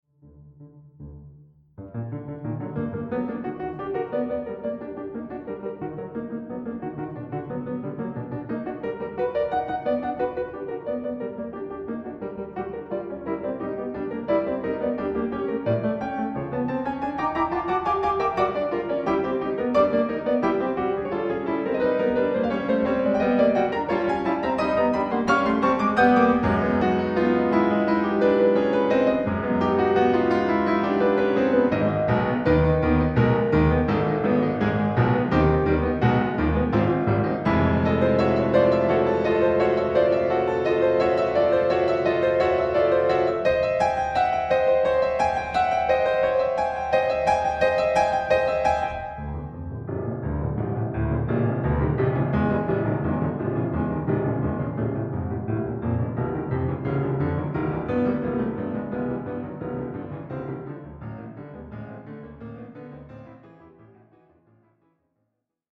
Australian classical music
Pianist